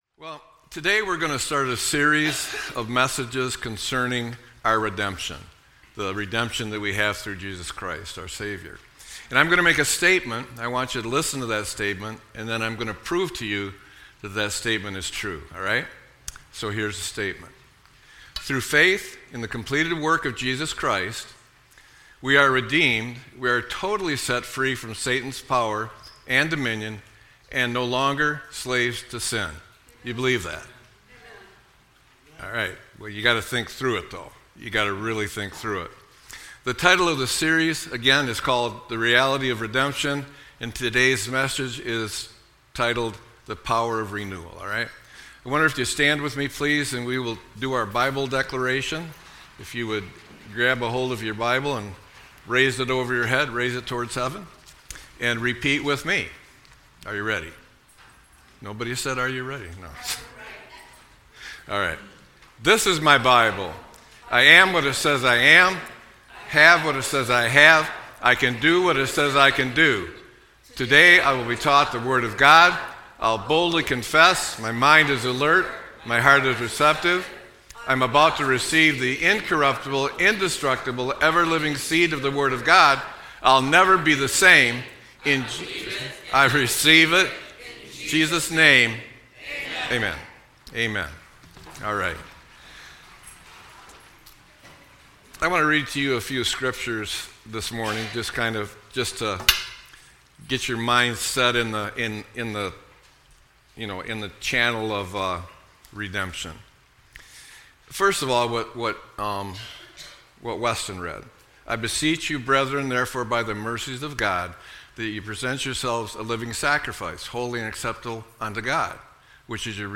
Sermon-9-28-25-smaller.mp3